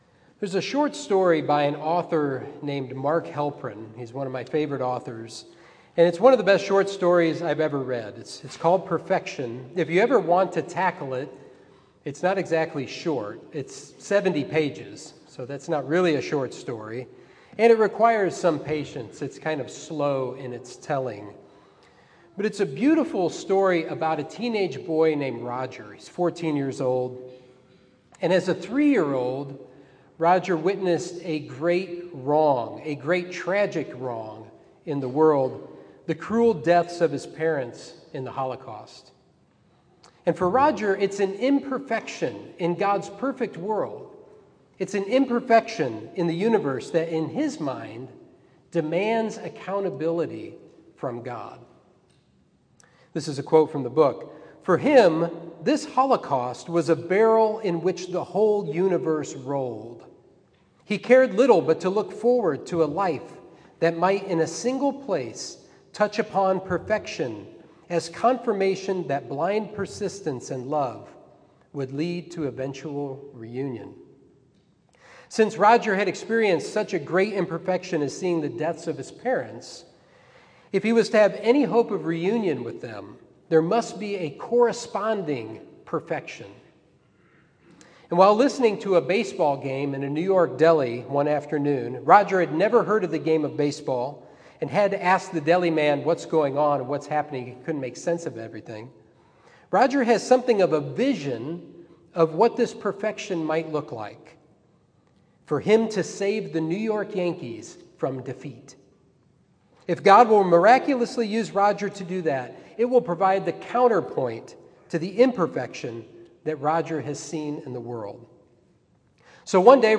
Sermon 8/15: Habakkuk: How to Lament – Trinity Christian Fellowship